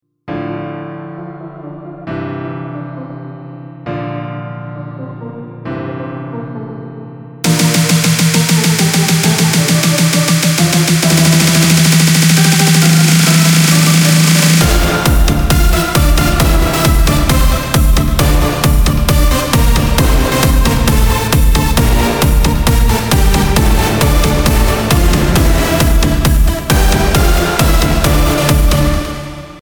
מטורף לדעתי חסר קצת אפקטים עם הפסנתר בהתחלה